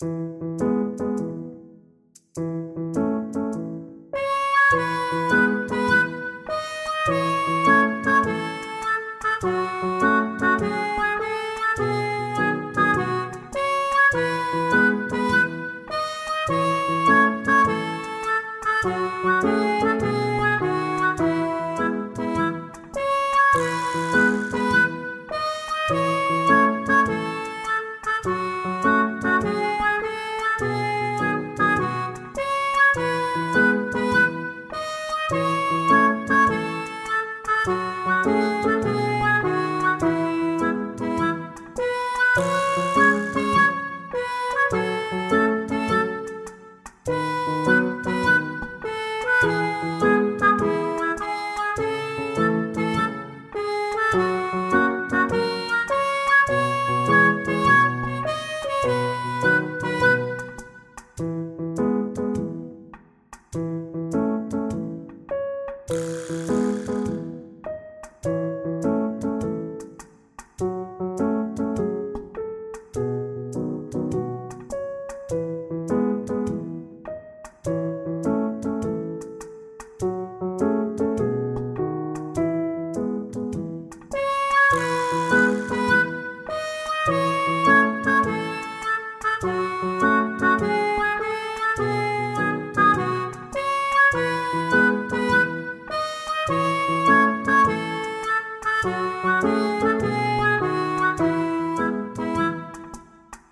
ファンタジー系フリーBGM｜ゲーム・動画・TRPGなどに！